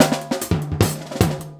LOOP39SD03-L.wav